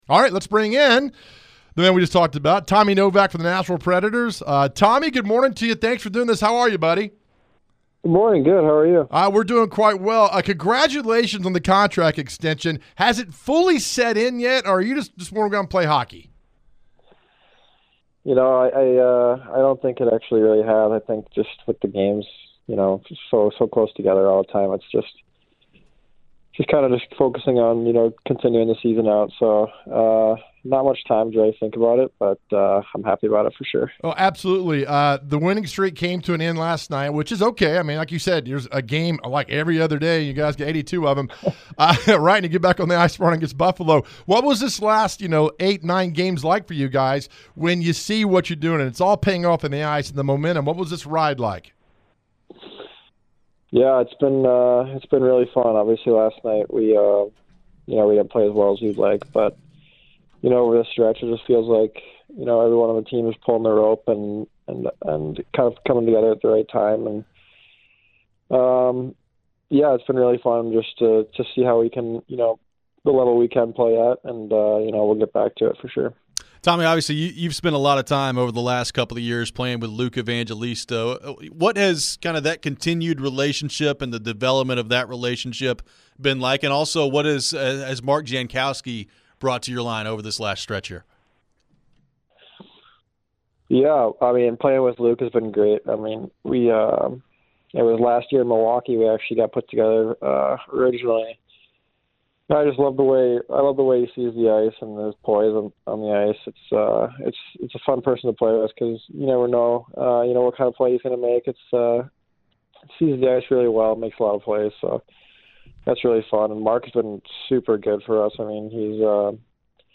Tommy Novak Interview (03-06-24)
Predators center Tommy Novak joins the Chase & Big Joe Show. Novak talks about last night's loss to Montreal in overtime. He elaborates about the eight-game win streak, and what that meant for the team.